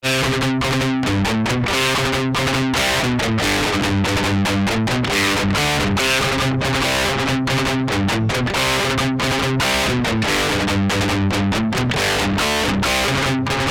В плаге всё лишнее отключил, в том числе и мощьник, все настройки один в один, кроме "master gain", у ады там 4.5, плаг это значение пропускает, и у него 4.6 (реальная ада это значение пропускает соответсвенно).
Так по структуре перегруза вроде не сильно далеко от оригинала, в принципе похоже плюс-минус на мой взгляд, по частотке есть отличия конечно.